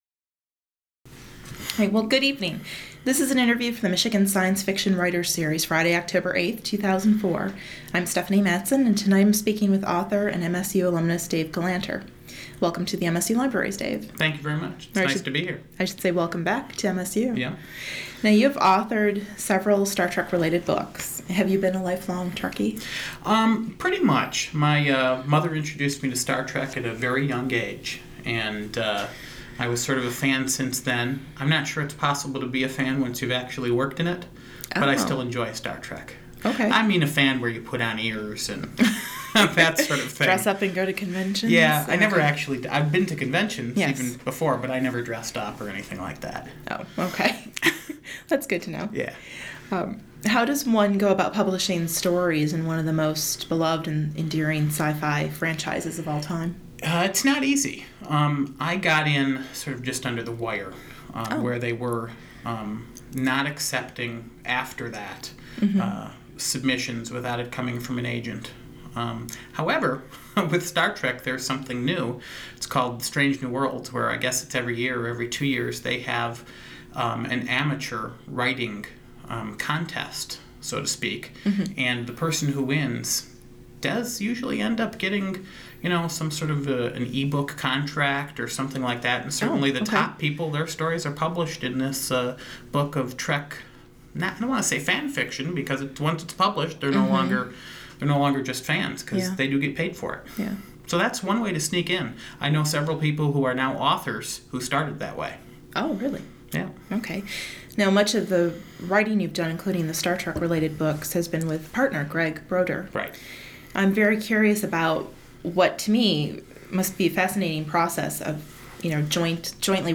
Michigan Writers Series. Held in the MSU Main Library.